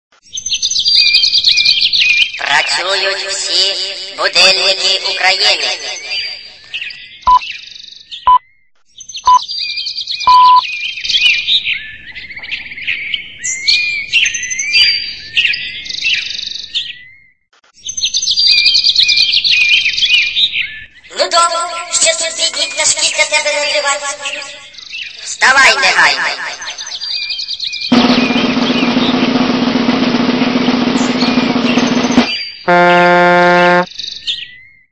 Тип: рінгтони